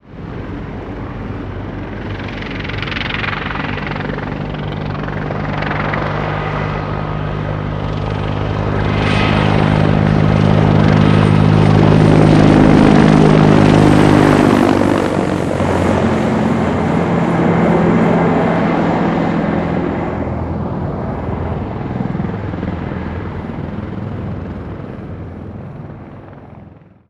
helicopter.L.wav